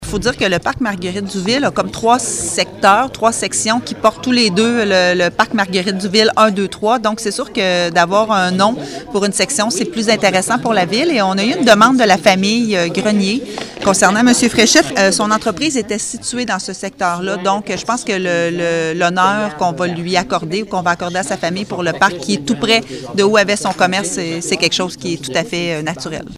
La mairesse, Geneviève Dubois :